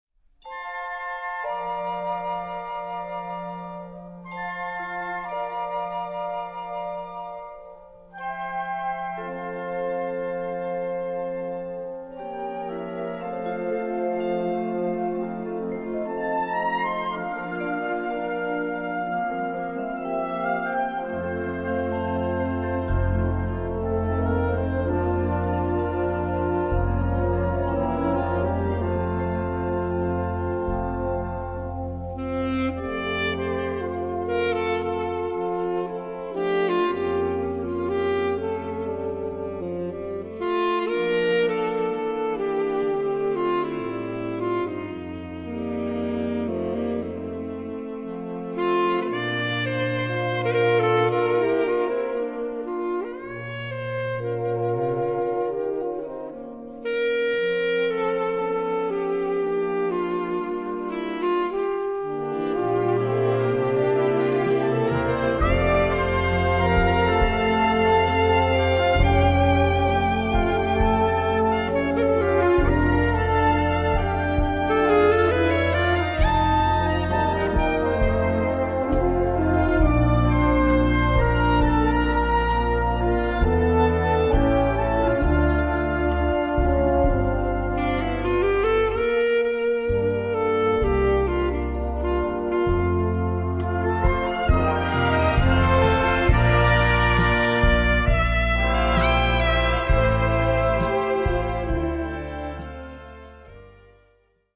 Besetzung: Blasorchester
Ballade
Blasorchesterbearbeitung